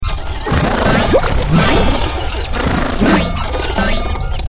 resources/phase_4/audio/sfx/tt_s_prp_sillyMeterPhaseFour.mp3 at e1639d5d6200f87d3312cfc8c8d1bbb1f69aad5f